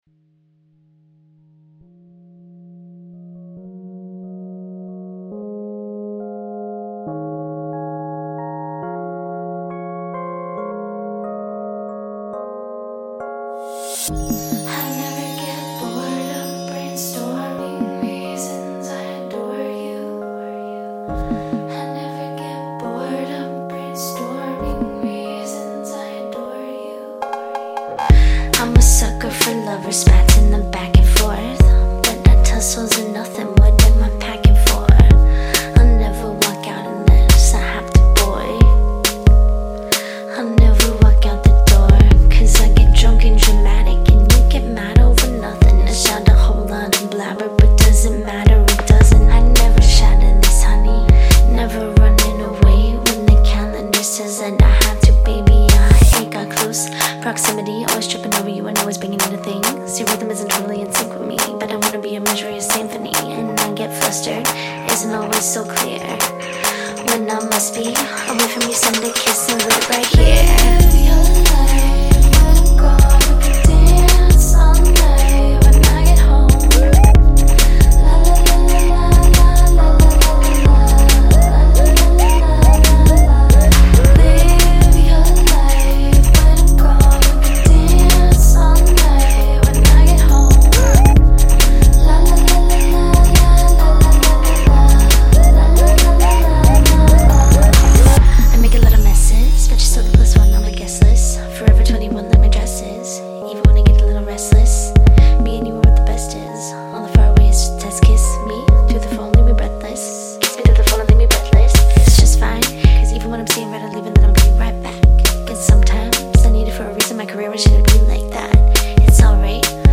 which is a kind of whispered bedroom house.